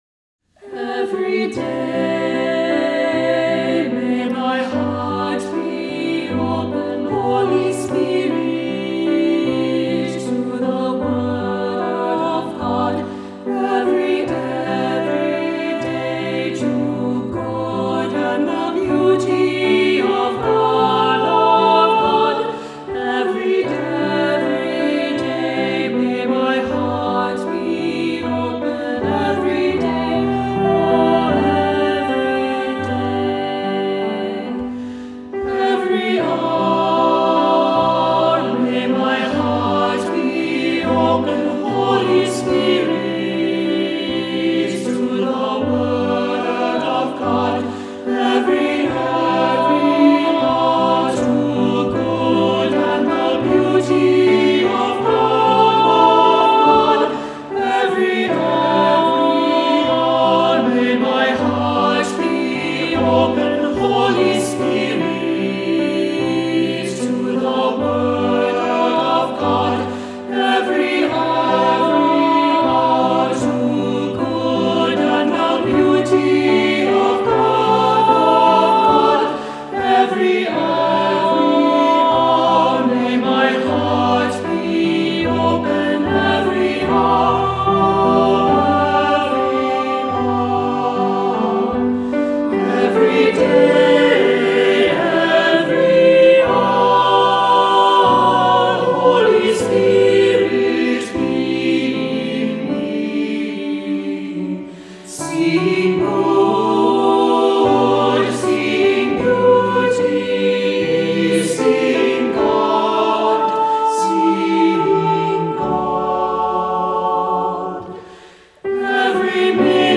Voicing: SA; SAB; Adults with children; Assembly